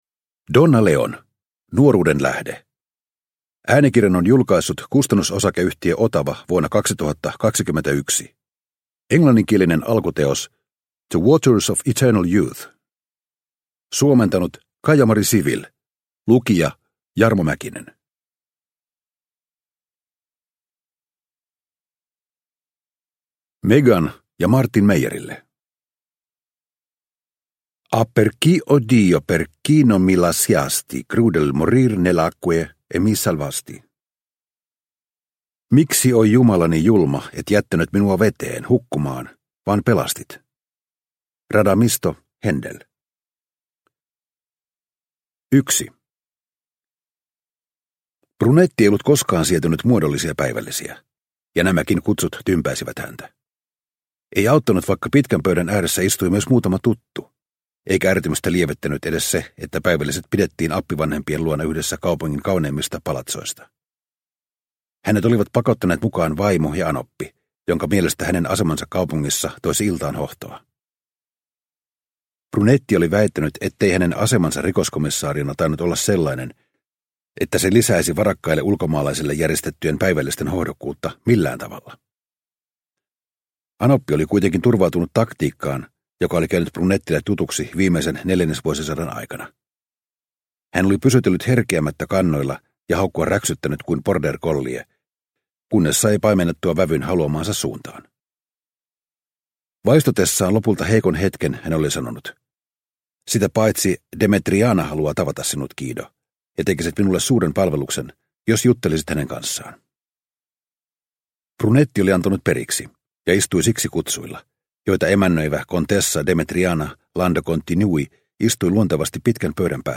Nuoruuden lähde – Ljudbok – Laddas ner